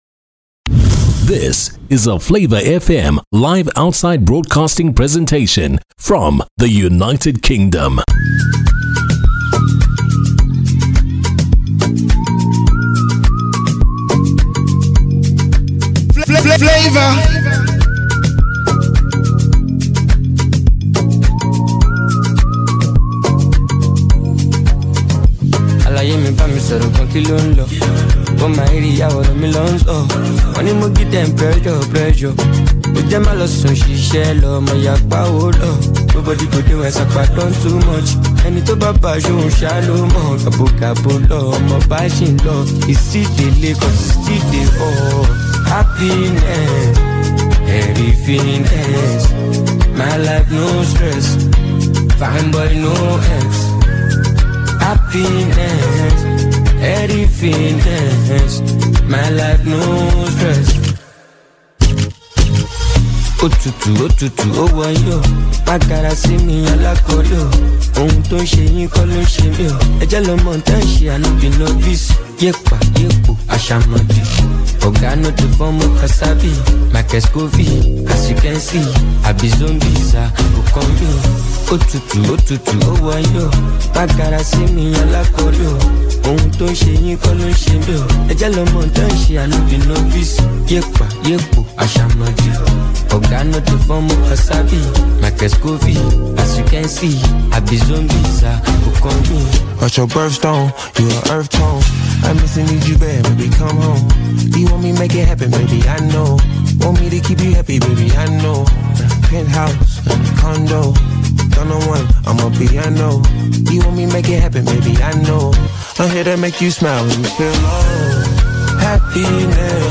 World Travel Market 2024 Outside Broadcast
PROGRAMME - WTM 2024 OUTSIDE BROADCAST.mp3